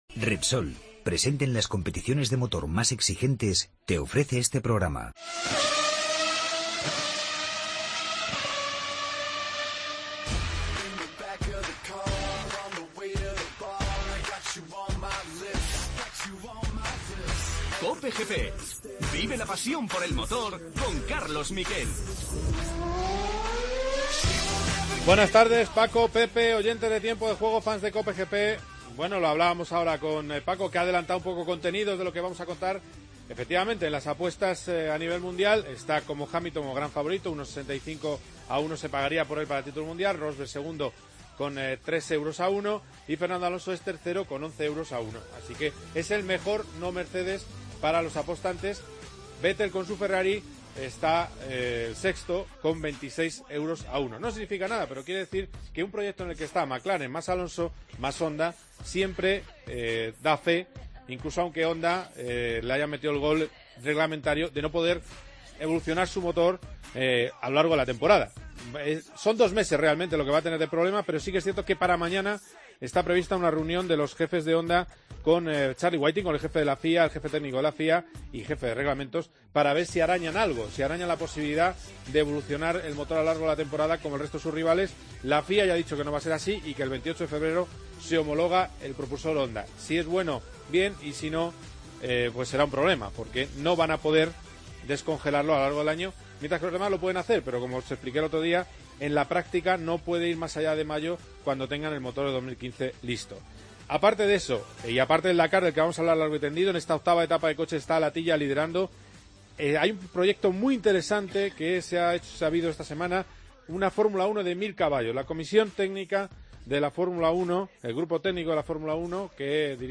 AUDIO: Titulares del día. Rally Dakar 2015. Entrevistamos a los dos aspirantes al triunfo en motos Marc Coma y Joan Barreda.